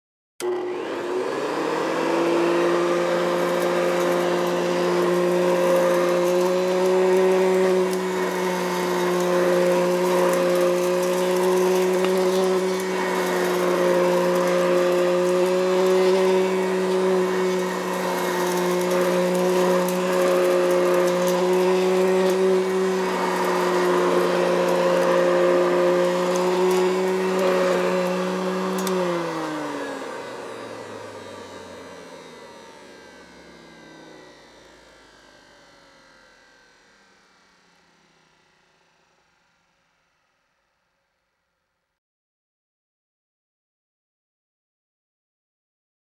household
Vacuum Cleaner On Dirt and Debris